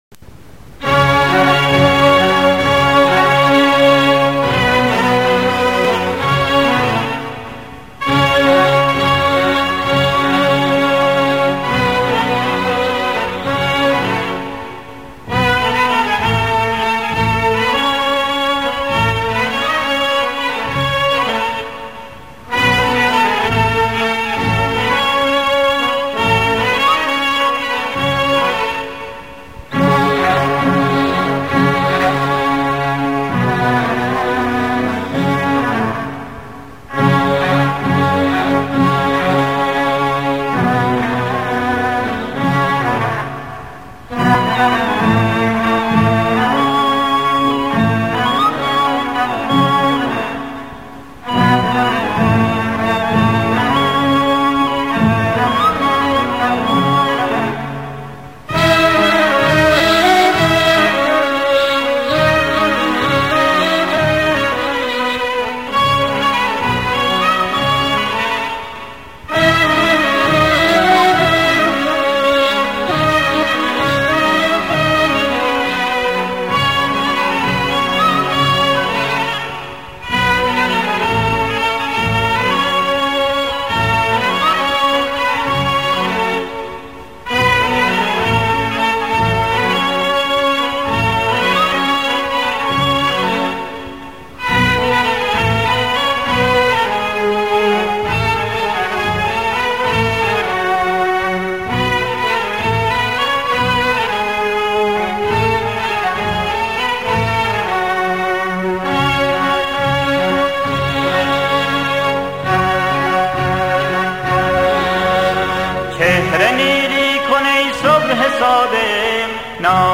آهنگ جانسوز